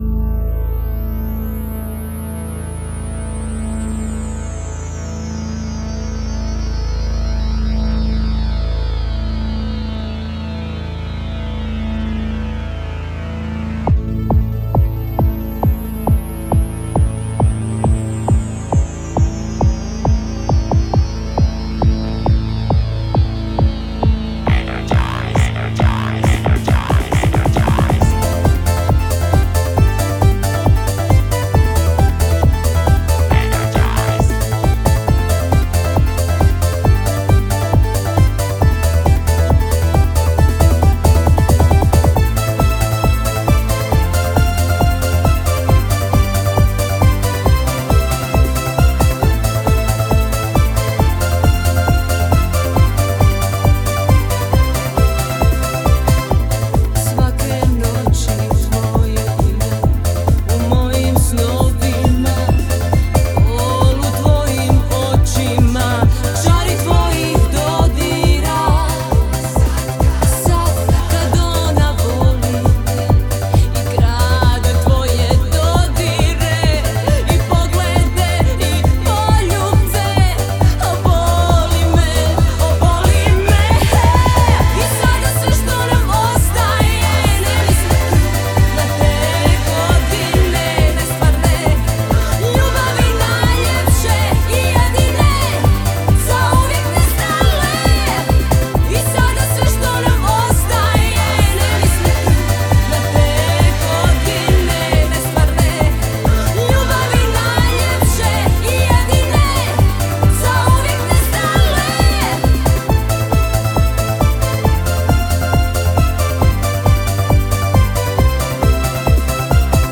Популярная хорватская поп-певица.